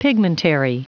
Prononciation du mot pigmentary en anglais (fichier audio)
Prononciation du mot : pigmentary